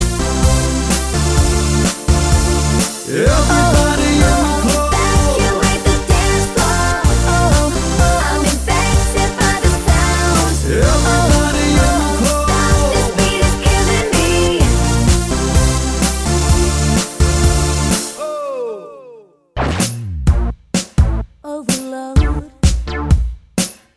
Female Solo Dance 3:25 Buy £1.50